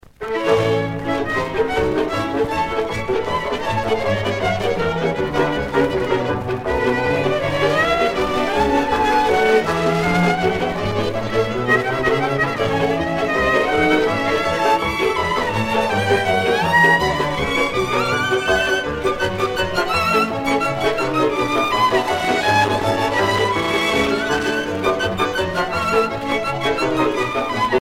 danse : csárdás (Hongrie)